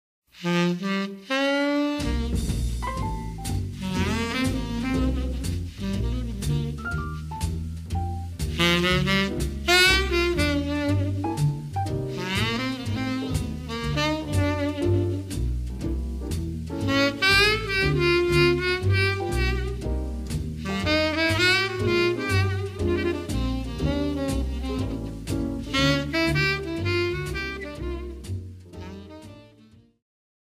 CD2の(12)-(20)はボーナス･トラックで、1957年7月、ニューポート･ジャズ祭でのステージの模様を収録。